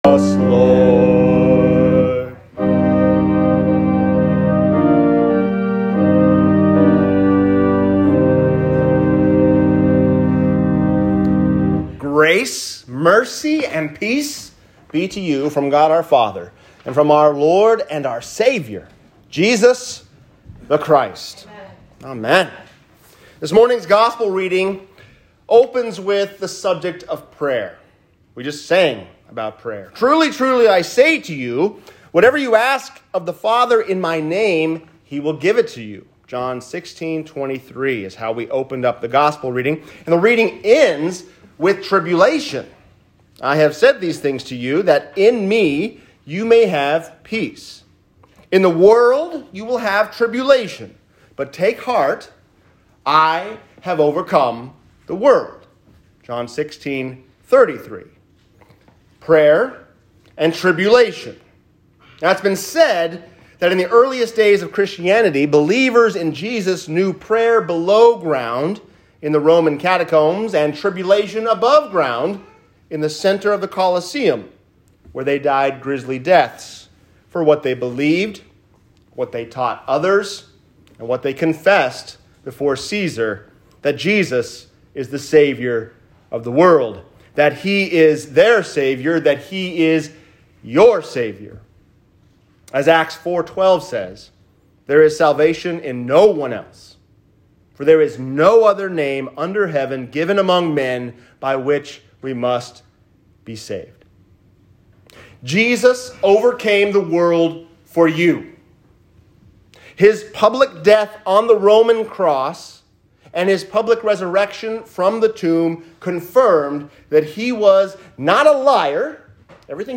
Prayer & Tribulation | Sermon